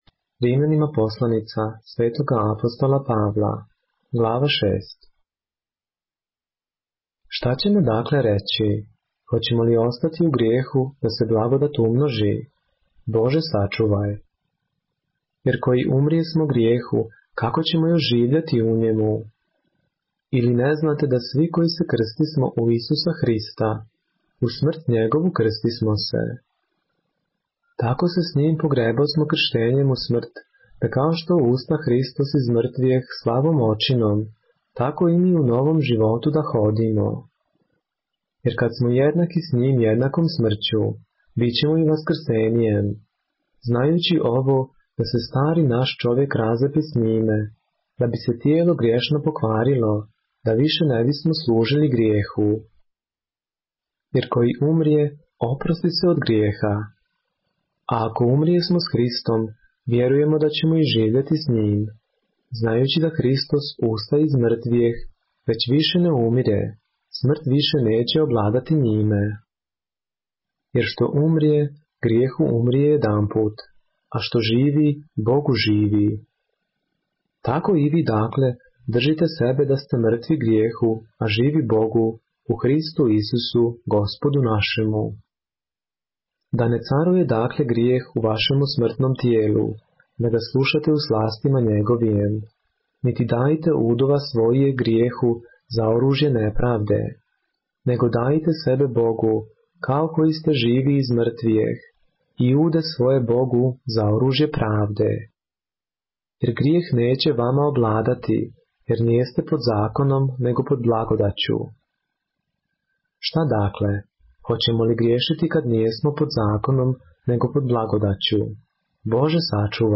поглавље српске Библије - са аудио нарације - Romans, chapter 6 of the Holy Bible in the Serbian language